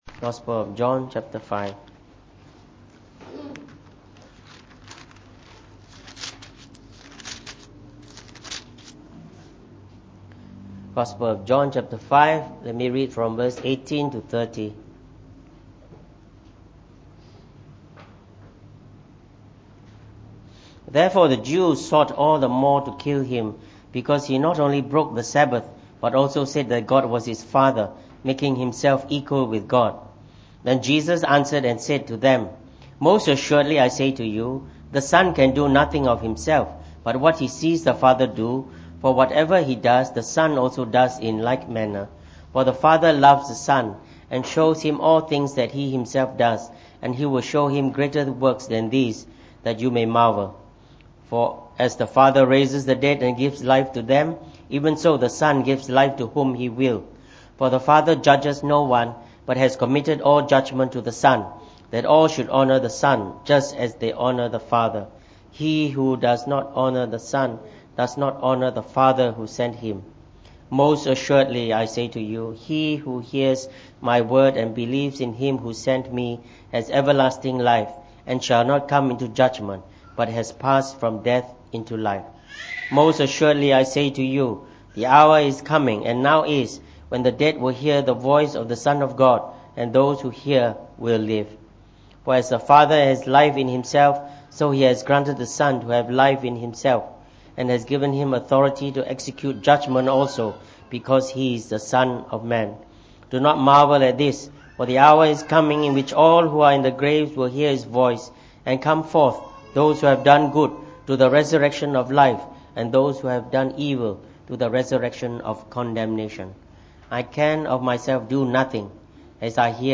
From our series on the Titles of Jesus Christ delivered in the Evening Service.